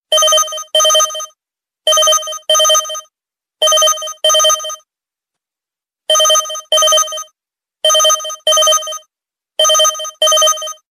Klassisk, Klassisk Telefon, Android